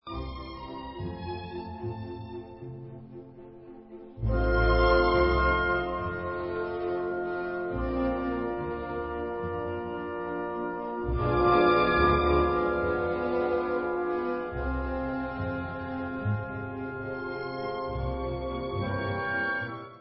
sledovat novinky v oddělení Klavírní koncerty
Klasika